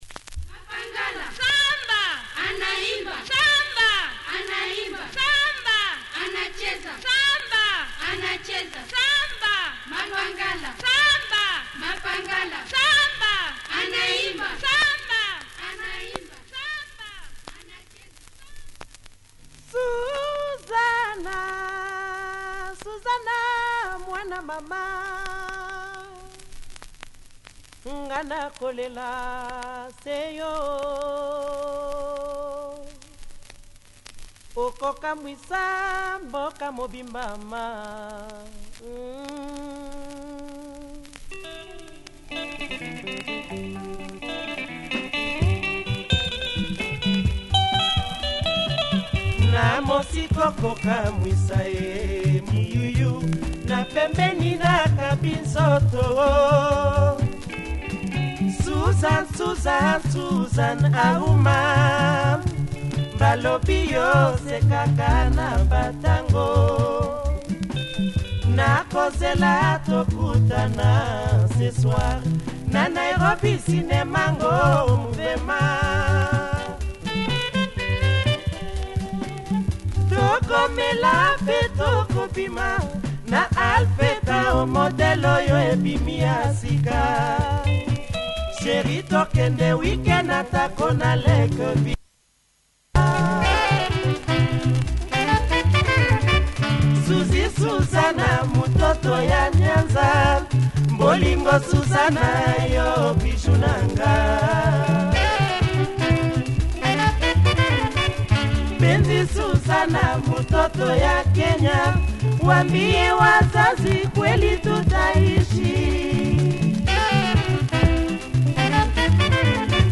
Top drawer Lingala tune
lovely intro and great sax play throughout!